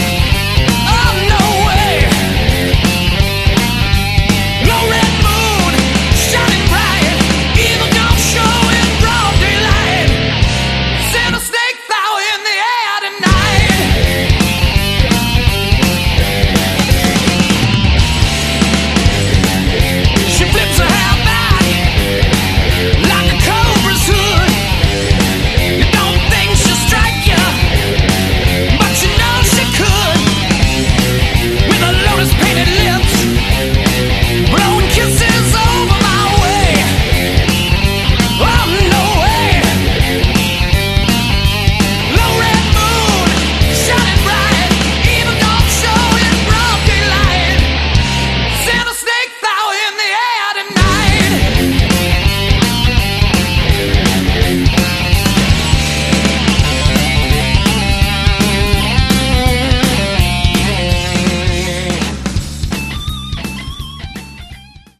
Category: Hard Rock
bass
vocals
guitar
drums